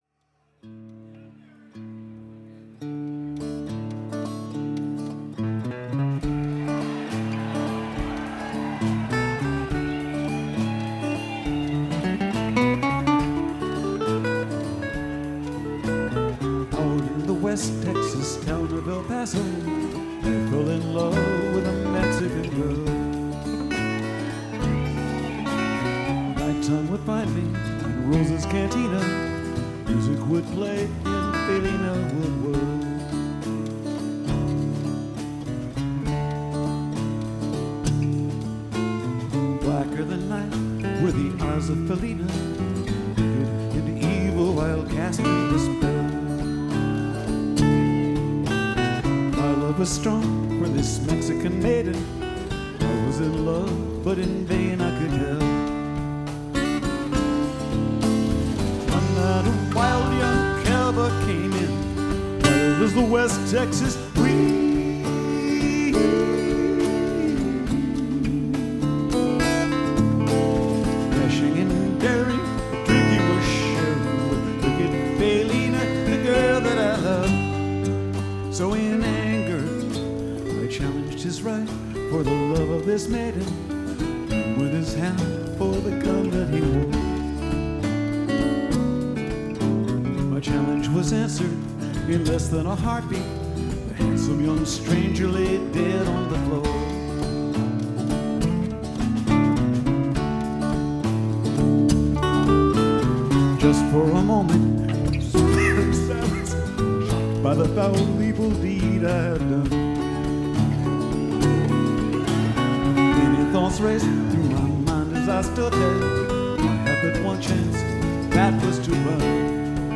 This is the front-end of Set #2.
chromatic harmonica
This is a very nice soundboard recording.